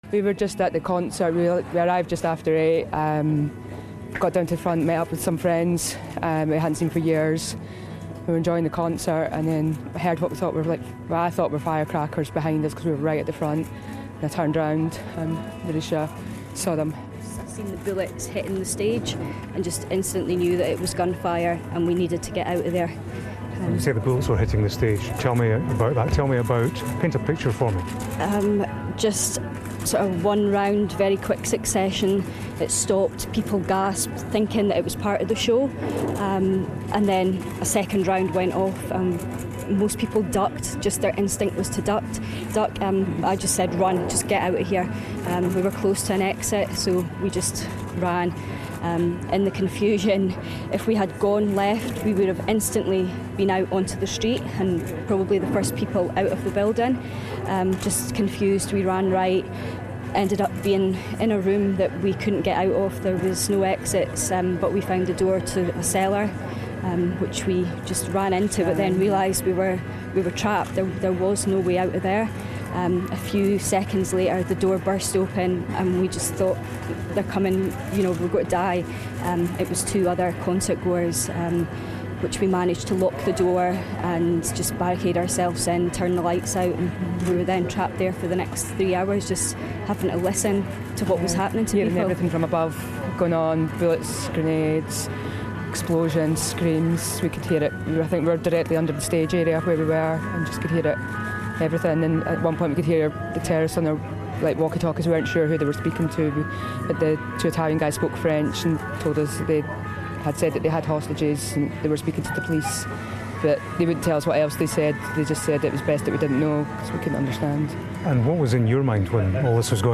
as they arrived back at Edinburgh airport...